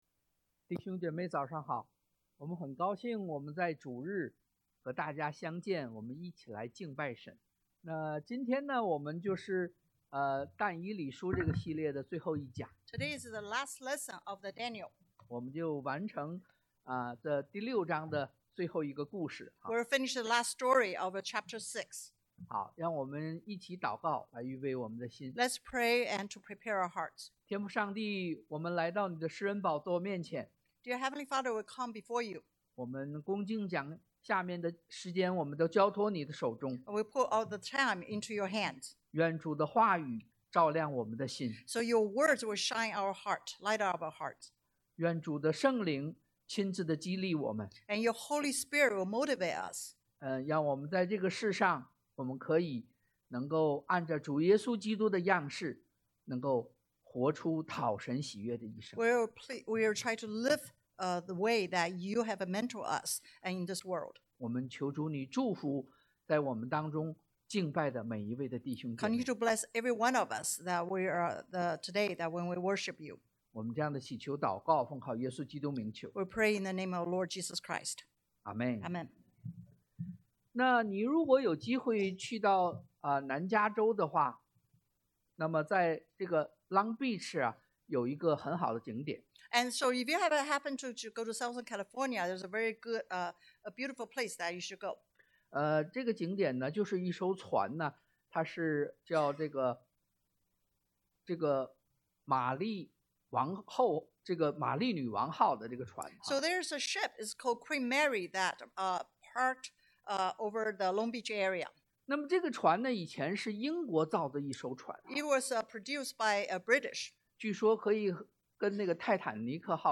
但 Dan 6:15-28 Service Type: Sunday AM 1.